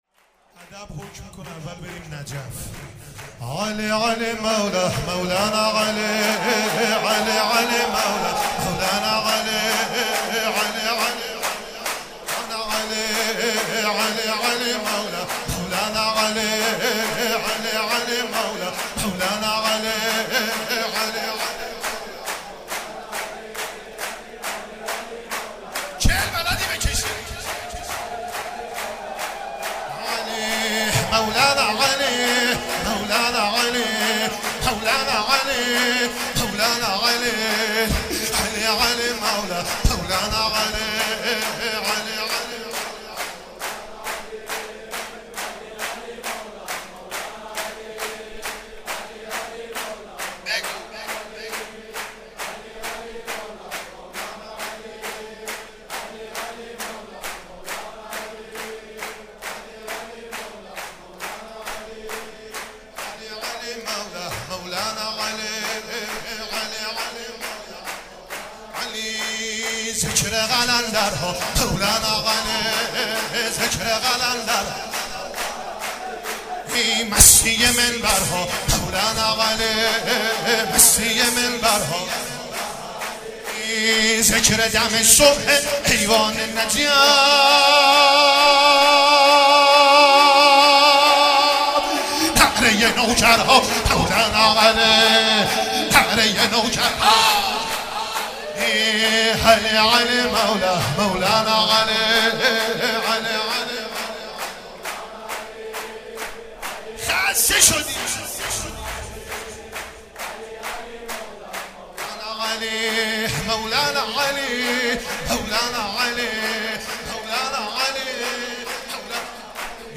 مراسم عزاداری شب چهارم محرم - چهارشنبه 14 مهر 1395
چهاراه شهید شیرودی حسینیه حضرت زینب (سلام الله علیها)